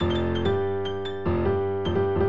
Piano Nudes Mve 3 Doubled Phrases.wav